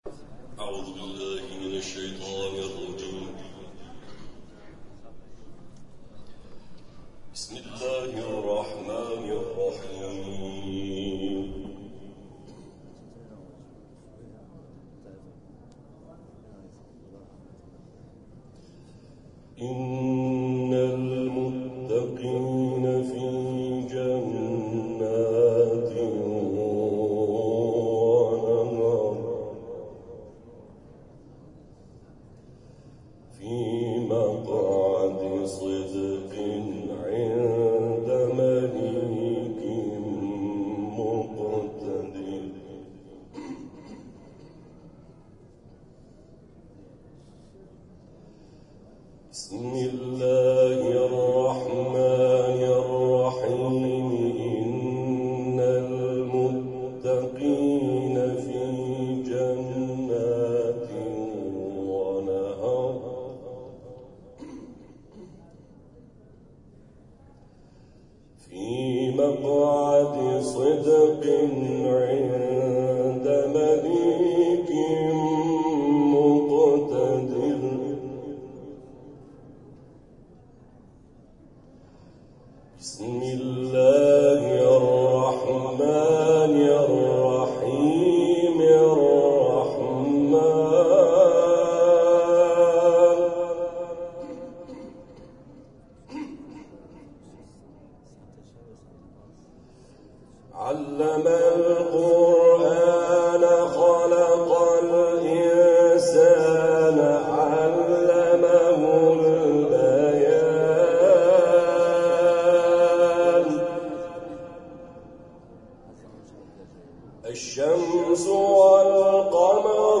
در پایان تلاوت‌های این مراسم ارائه می‌شود.
تلاوت